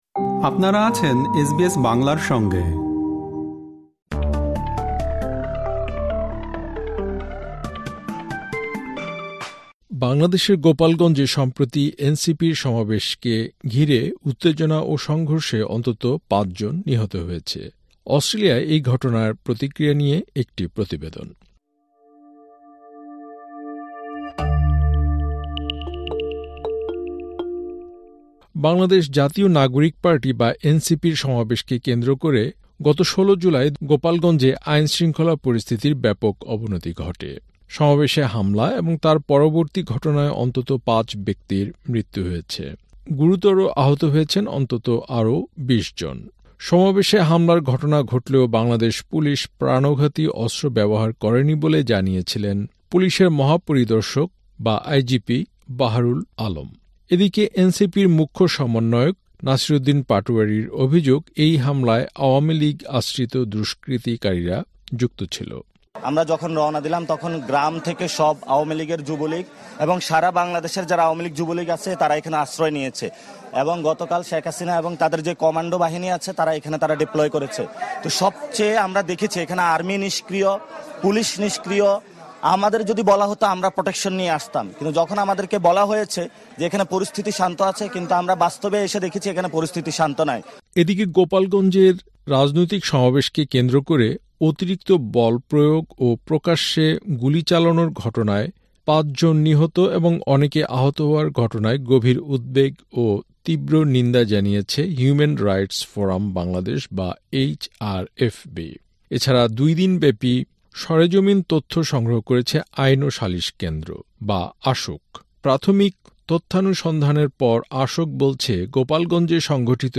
বাংলাদেশের গোপালগঞ্জে সম্প্রতি এনসিপি-র সমাবেশকে ঘিরে উত্তেজনা ও সংঘর্ষে অন্তত পাঁচ জন নিহত হয়েছে। অস্ট্রেলিয়ায় এই ঘটনার প্রতিক্রিয়া নিয়ে একটি প্রতিবেদন।